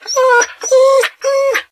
bdog_hurt_2.ogg